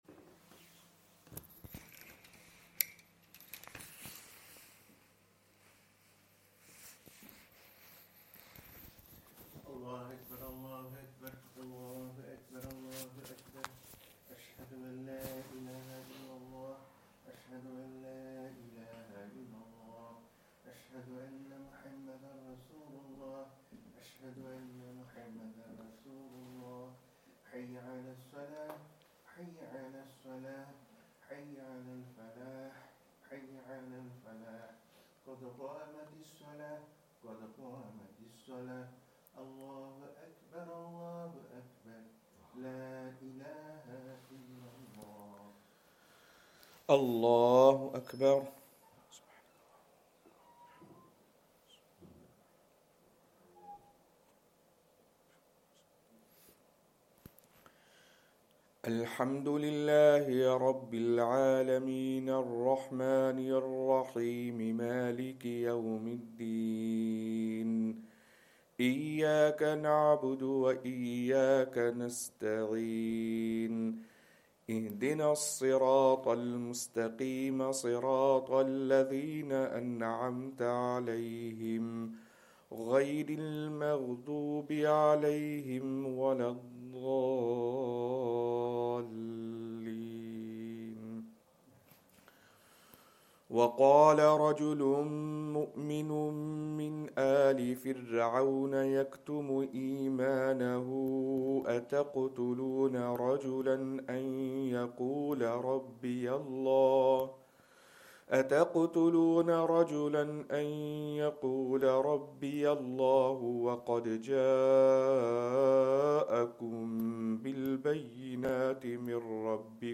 Fajr Surat ul Mo'min
Madni Masjid, Langside Road, Glasgow